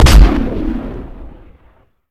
Rifle2.ogg